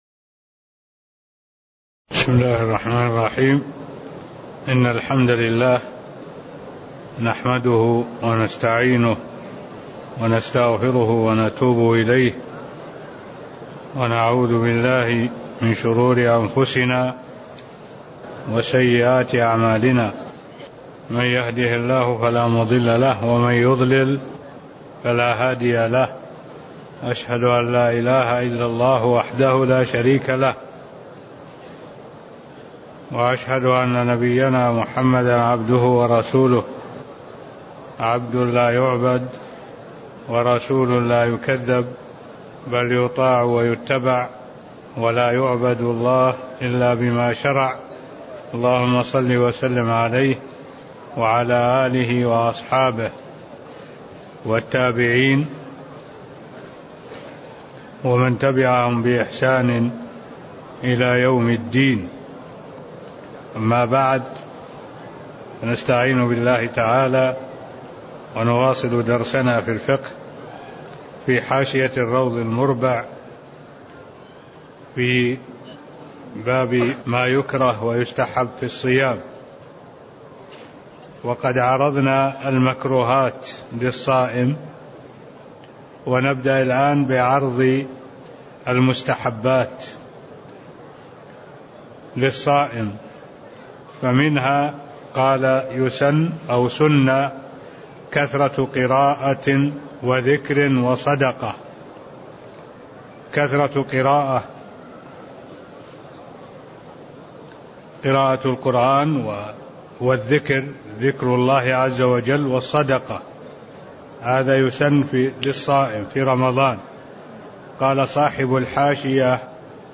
المكان: المسجد النبوي الشيخ: معالي الشيخ الدكتور صالح بن عبد الله العبود معالي الشيخ الدكتور صالح بن عبد الله العبود باب ما يكره ويستحب في الصوم (قول المصنف سن كثرة قراءةٍ وذكرٍ) (09)